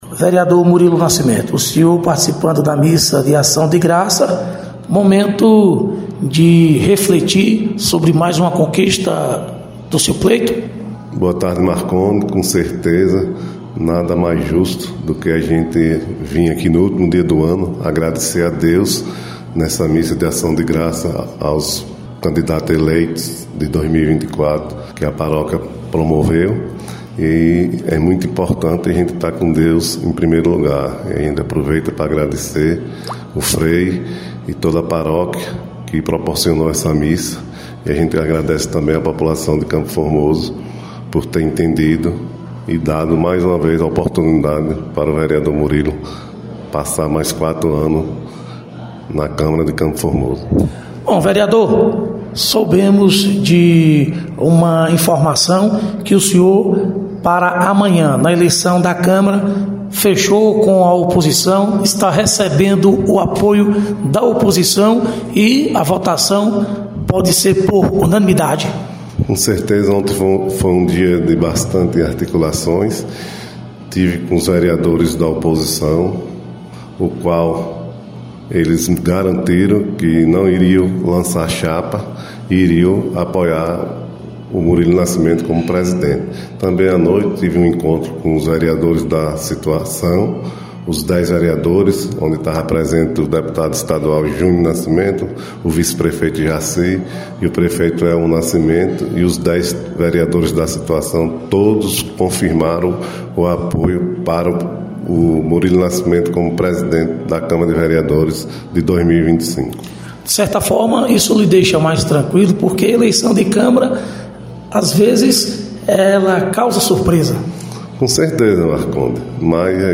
Vereador Murilo Nascimento participação da missa em ação de graça aos eleitos de Campo Formoso